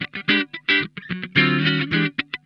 HOT CHUG 2.wav